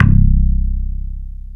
BASS1.mp3